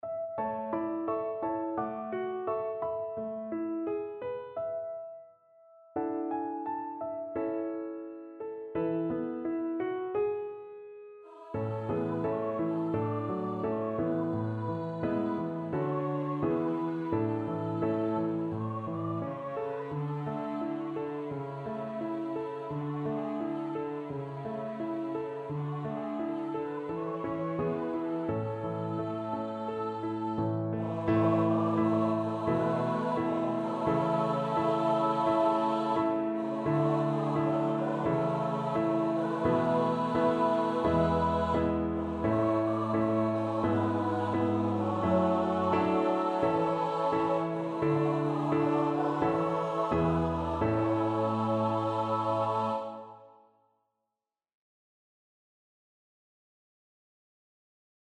Notensatz 1 (Gesang und Instrument mit Instrumenten)